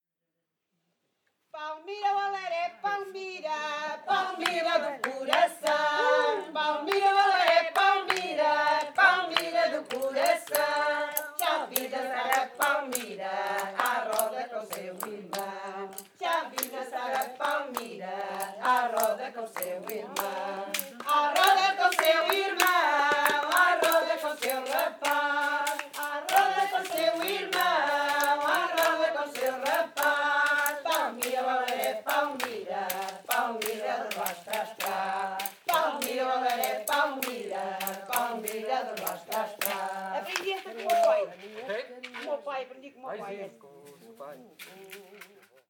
Quando o comboio de Viseu partiu. Calde a 22 Setembro 2016.